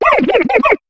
Cri de Brindibou dans Pokémon HOME.